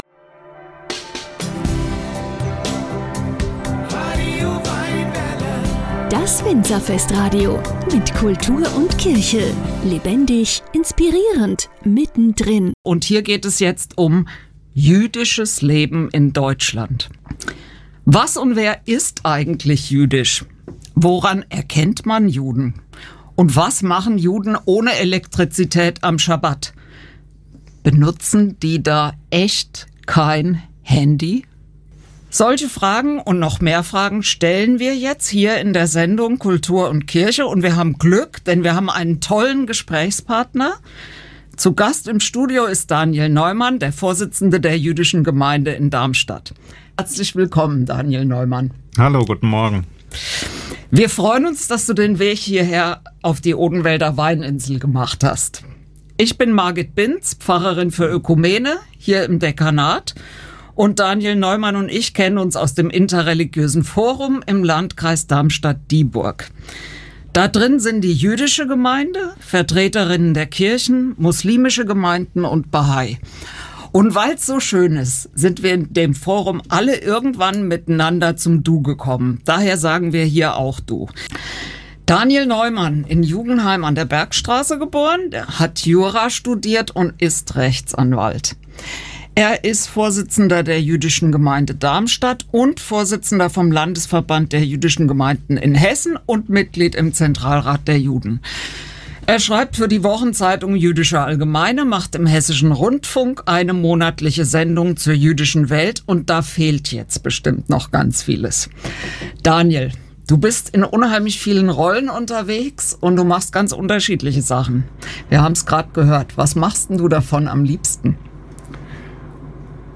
Das Laubhüttenfest, von dem am Ende die Rede ist, fiel dieses Jahr auf den 7. Oktober. Hier ist der Mitschnitt, ohne Musikbeiträge.